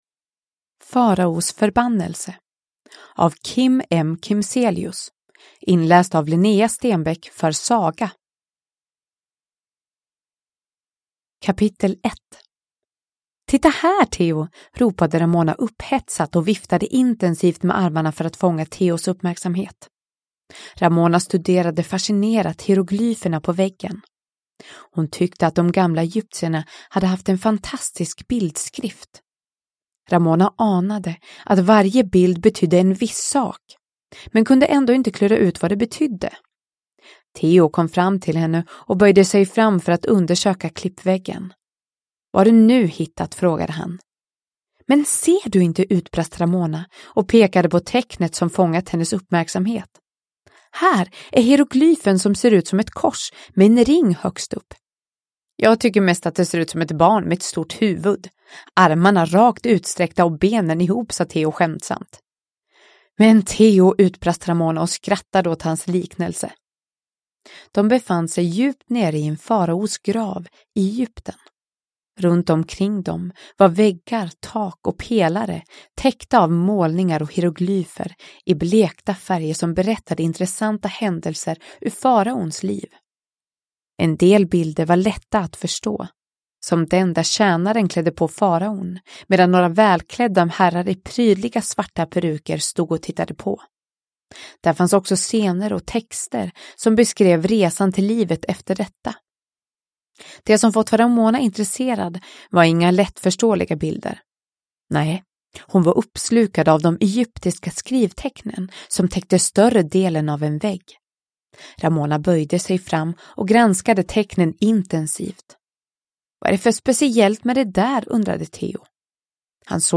Faraos förbannelse / Ljudbok